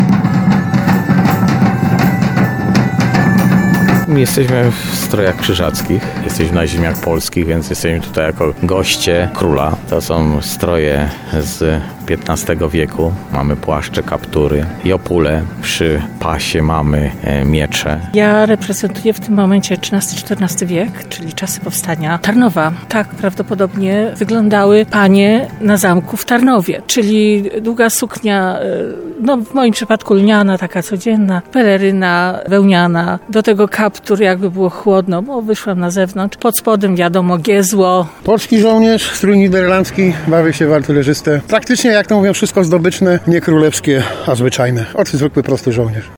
Król wraz ze swoją świtą, rycerze i damy dworu – to postacie, które znalazły się w historycznym korowodzie, jaki przeszedł dzisiaj (07.03) przez centrum Tarnowa.
Słychać było dźwięki bębnów i radosne okrzyki „Wiwat Tarnów!”. Pojawili się przedstawiciele grup rekonstrukcyjnych oraz mieszkańcy.